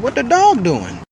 dog.wav